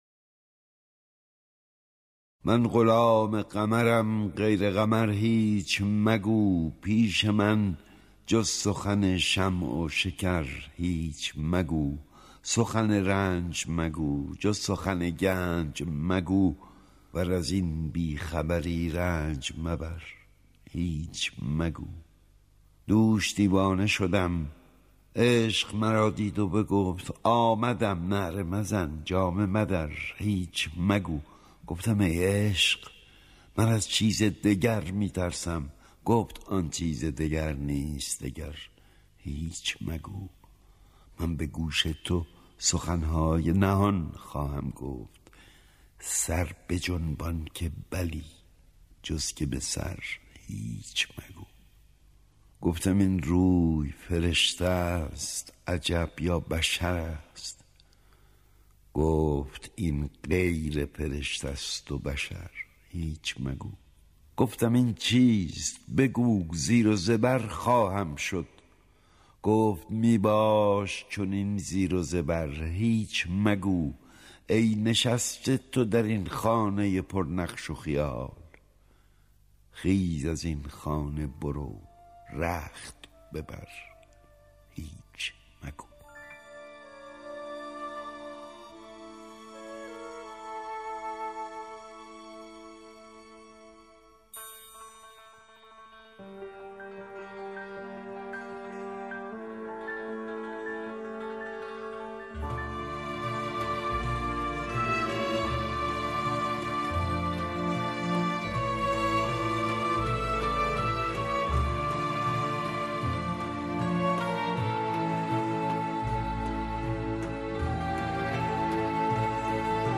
Reading: By my beloved Ahmad Shamloo